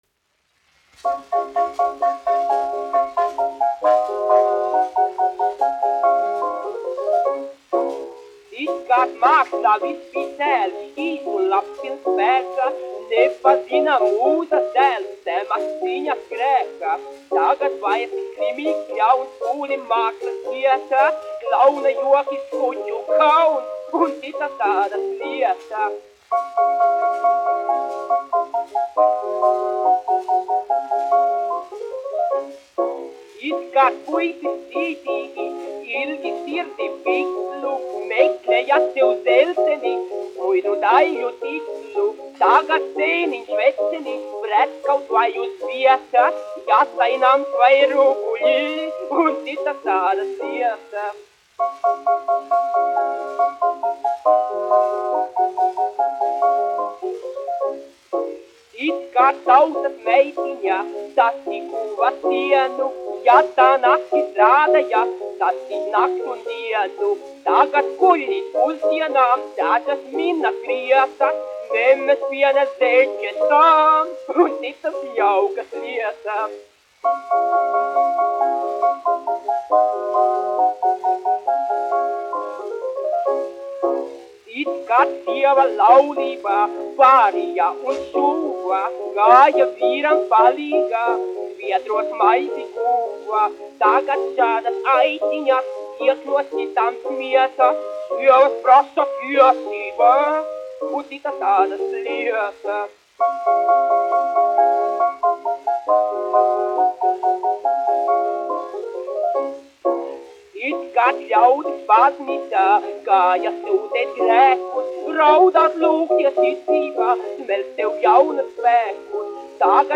1 skpl. : analogs, 78 apgr/min, mono ; 25 cm
Populārā mūzika
Humoristiskās dziesmas
Latvijas vēsturiskie šellaka skaņuplašu ieraksti (Kolekcija)